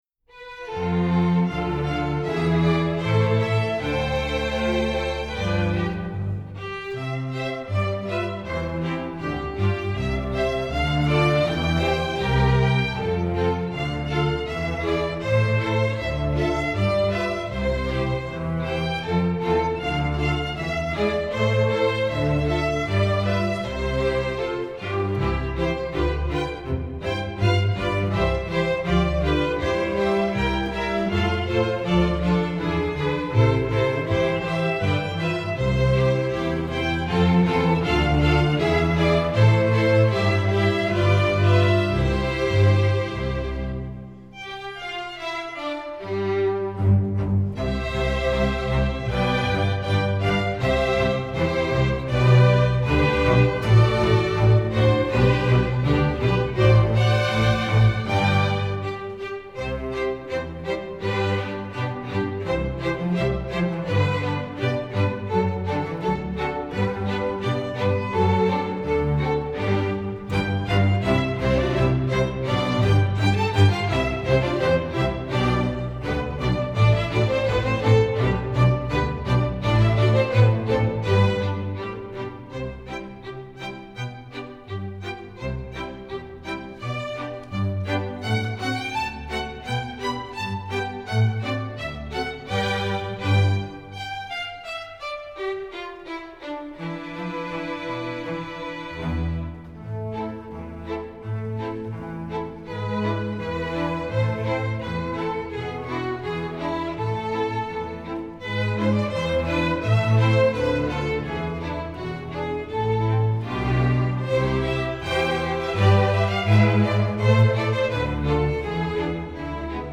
Composer: Traditional Russian
Voicing: String Orchestra